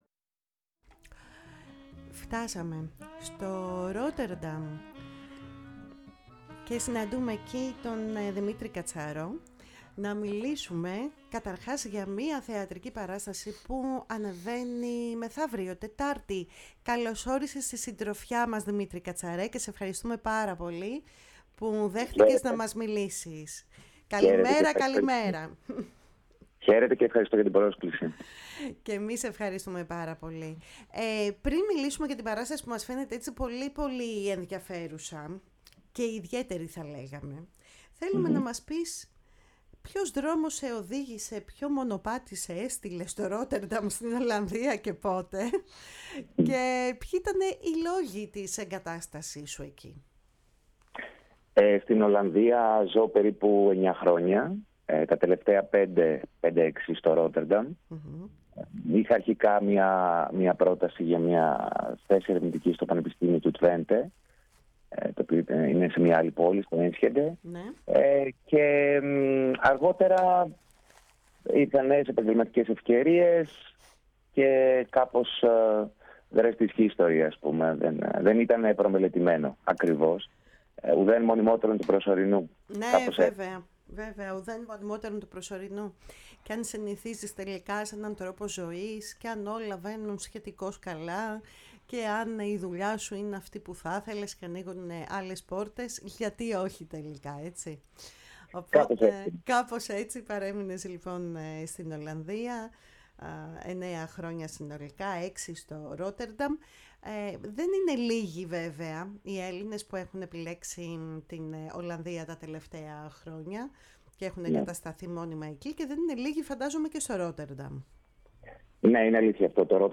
Κουβεντες Μακρινες ΣΥΝΕΝΤΕΥΞΕΙΣ